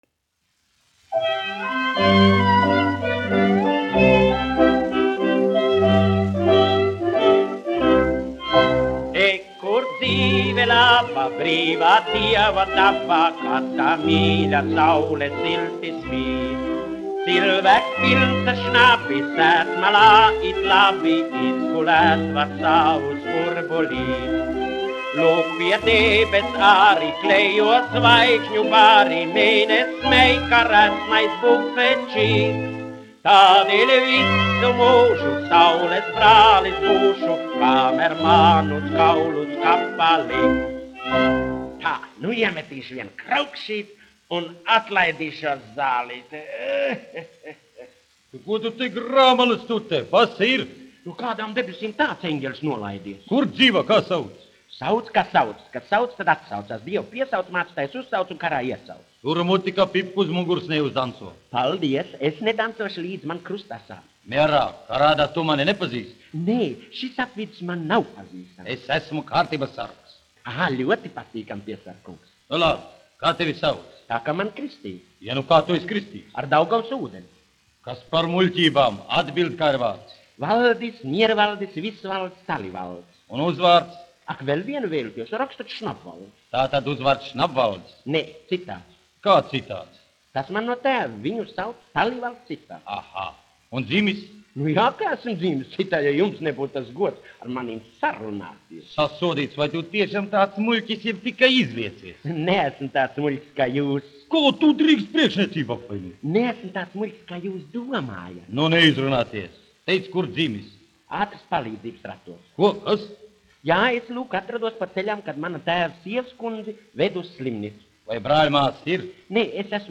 1 skpl. : analogs, 78 apgr/min, mono ; 25 cm
Humoristiskās dziesmas
Latvijas vēsturiskie šellaka skaņuplašu ieraksti (Kolekcija)